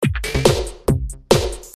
钢琴旋律110
Tag: 102 bpm Classical Loops Piano Loops 6.33 MB wav Key : Unknown